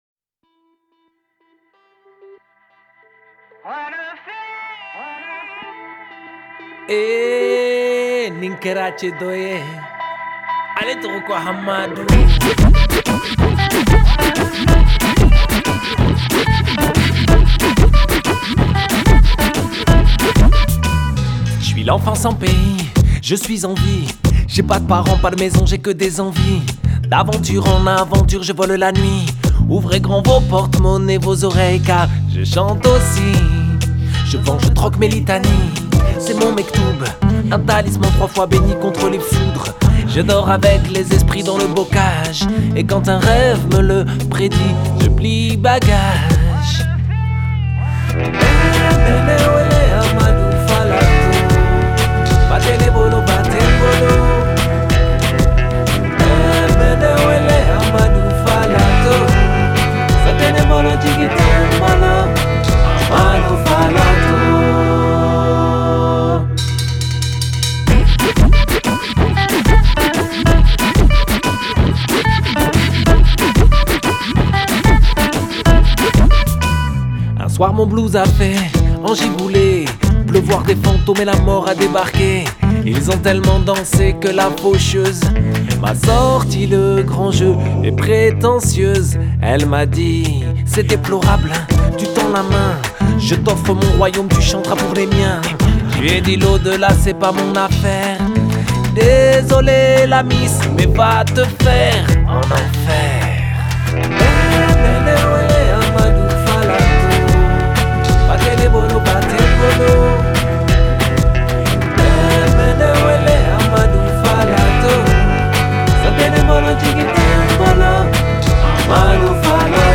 Genre: World, Folk